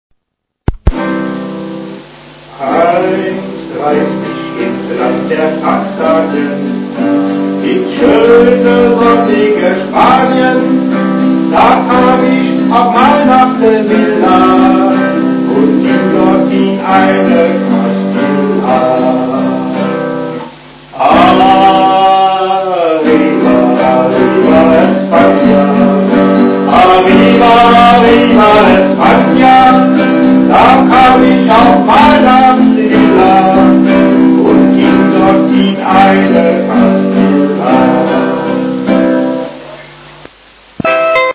Melodie -